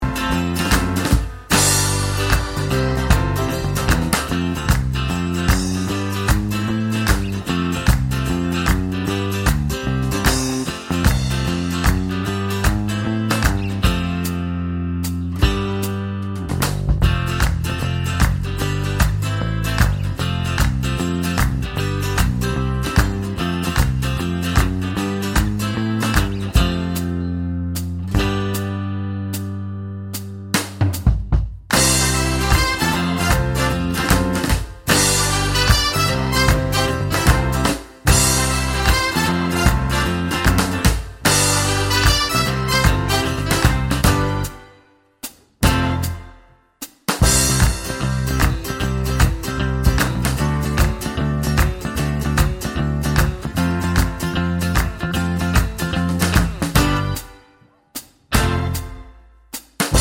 Minus Sax Solo Pop (1970s) 3:51 Buy £1.50